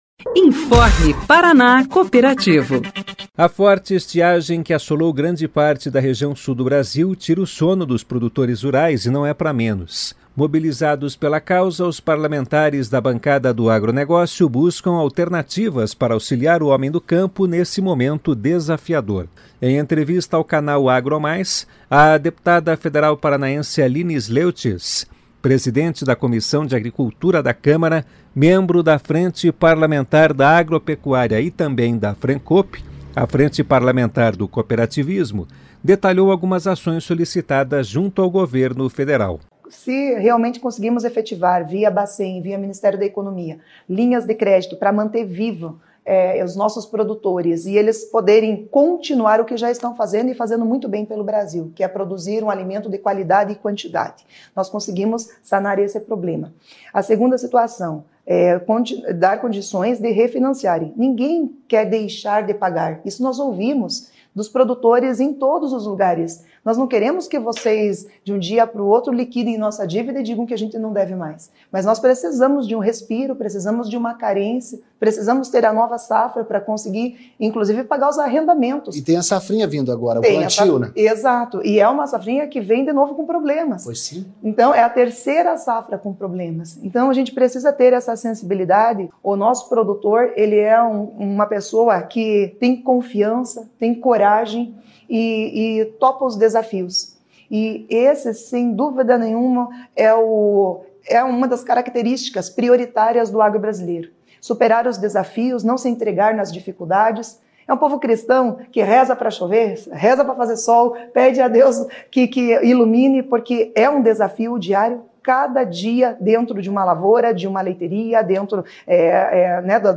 Mobilizados pela causa, os parlamentares da bancada do agronegócio buscam alternativas para auxiliar o homem do campo nesse momento desafiador. Em entrevista ao canal "Agro Mais", a deputada federal paranaense Aline Sleujtes, Presidente da Comissão de Agricultura da Câmara, membro da FPA e da Frencoop, detalhou algumas ações solicitadas junto ao Governo Federal.